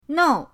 nou4.mp3